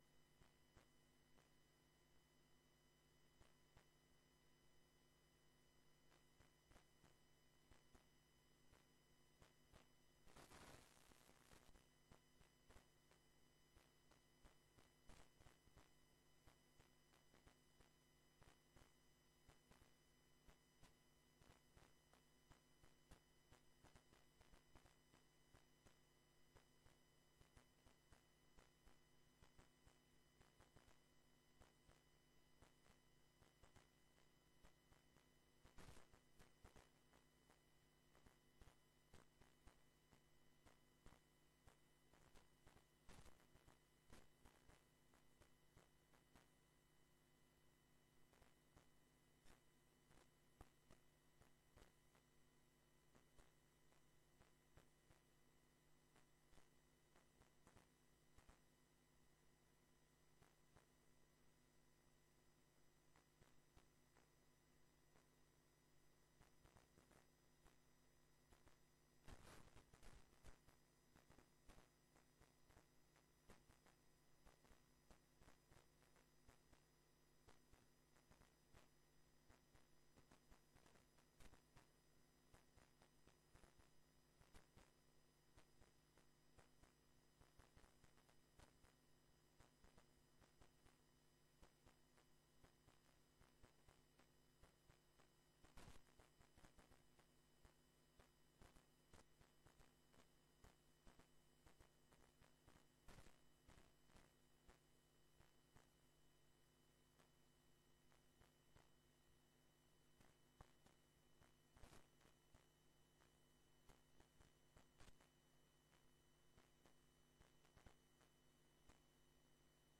Locatie: raadzaal Voor de behandeling van het rekenkamerrapport over de wijk- en dorpsraden worden tevens de leden van de raadscommissie S&I-M&B uitgenodigd.